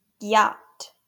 Gyatt (/ɡjɑːt/